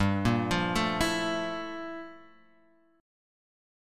G Minor 13th